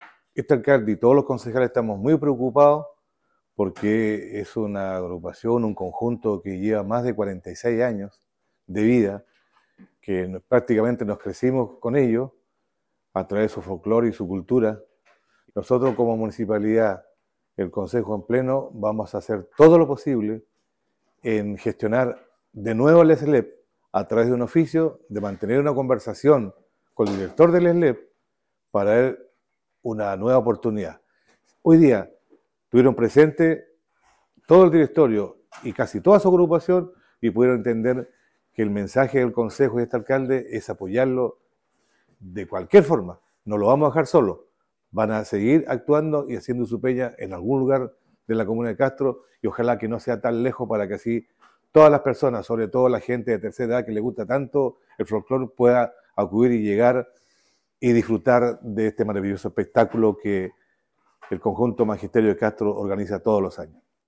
alcalde-Elgueta-por-Pena-Coche-Molina.mp3